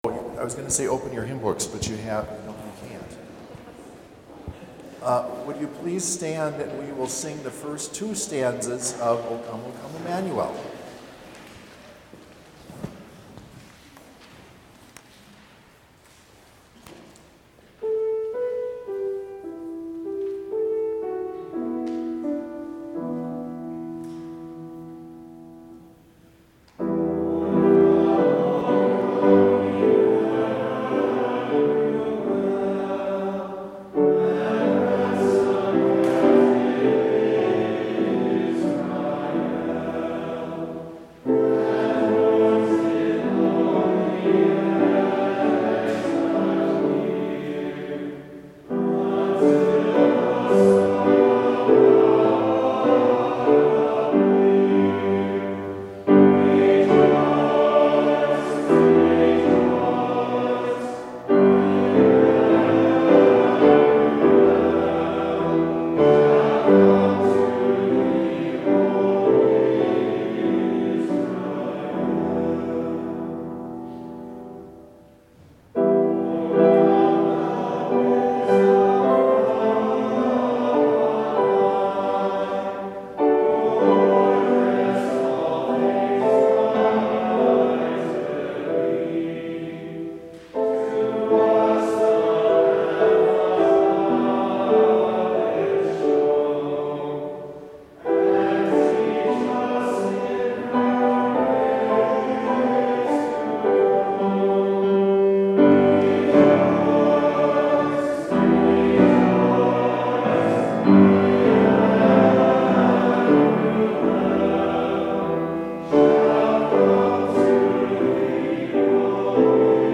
Complete service audio for Chapel - December 10, 2020